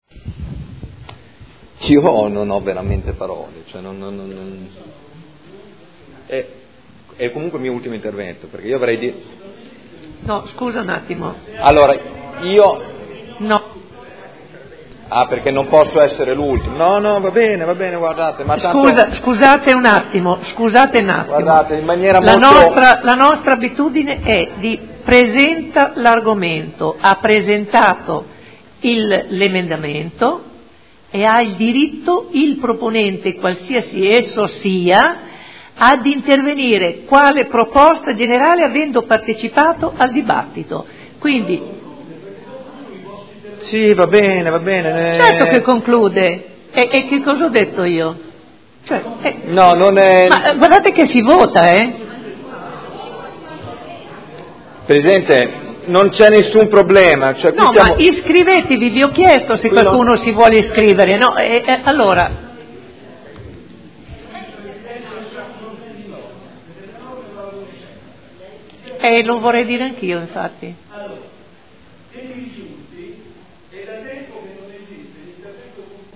Michele Barcaiuolo — Sito Audio Consiglio Comunale